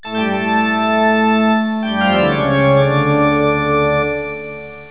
toccata.wav